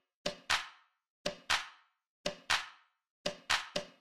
1 channel
claps.ogg